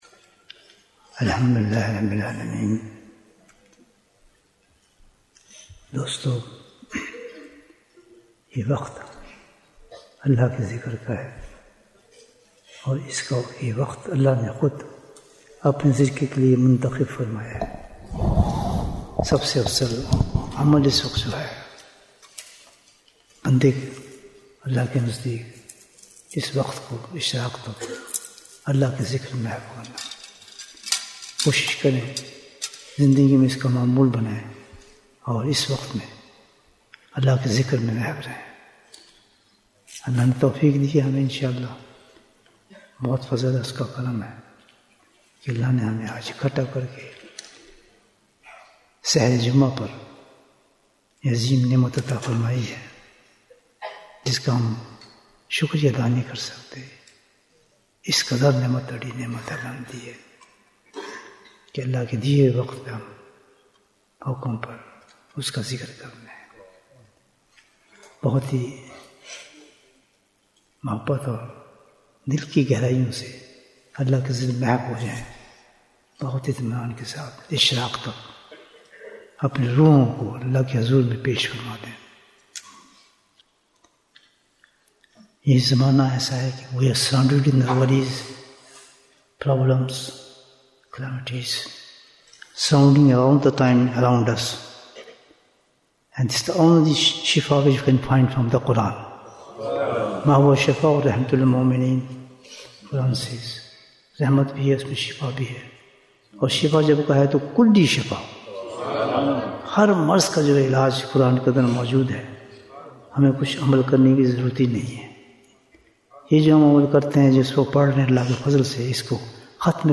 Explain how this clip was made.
Annual Ijtema 2025 After Fajr until Sunrise - this time is stipulated by Allah ta'ala for the act of dhikr.